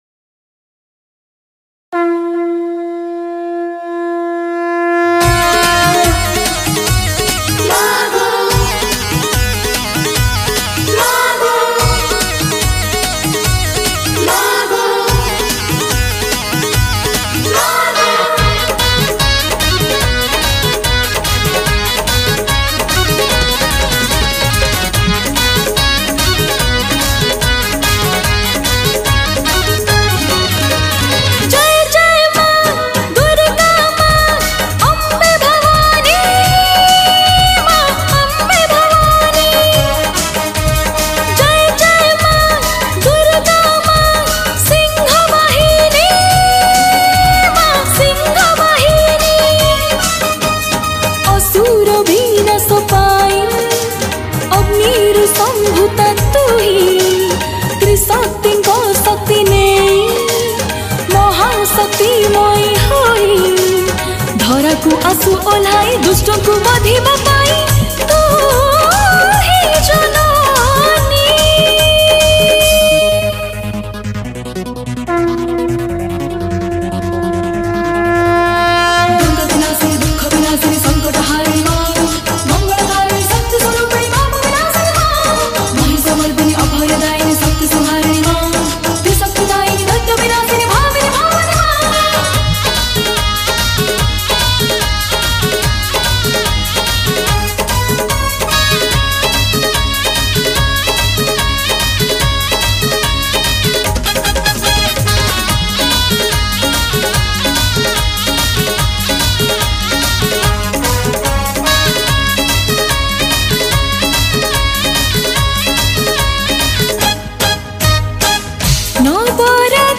New Odia Durga Puja Special Superhit Bhajan Song 2022